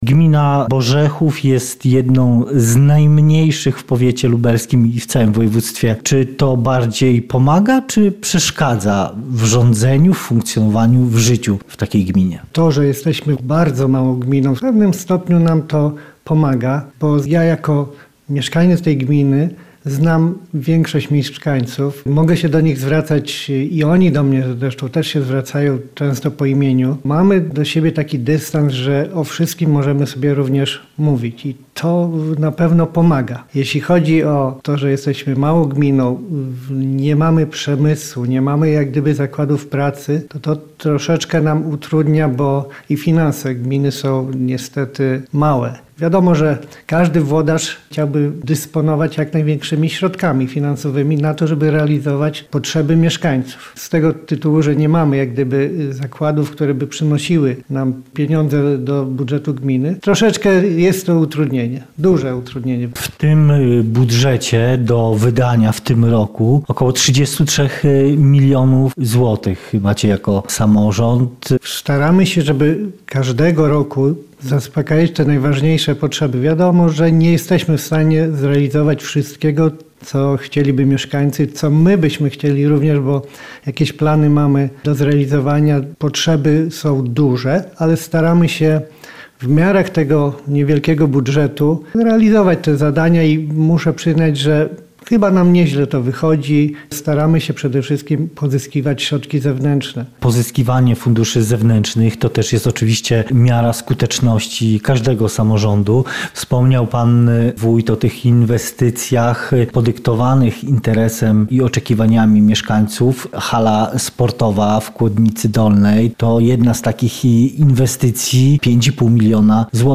O jej znaczeniu, ale także o sposobach na szukanie oszczędności w gminnym budżecie z wójtem gminy Borzechów Edwardem Jarzynką rozmawiał